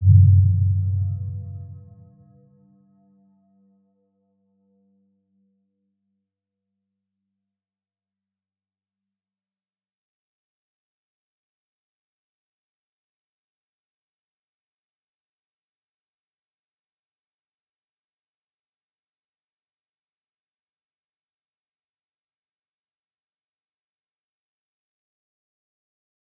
Round-Bell-G2-p.wav